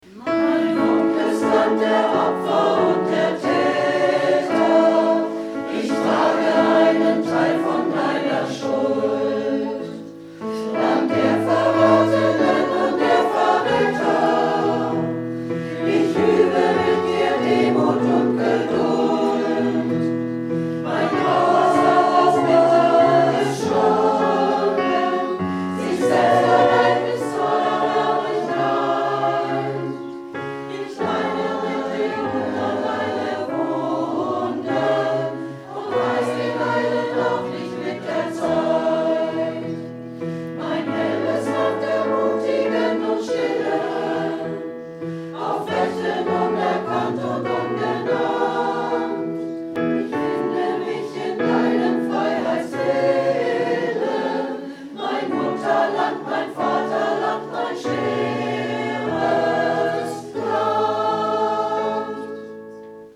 Reinhard Mey im Chor
Hörbeispiele vom ersten und zweiten Wochenende: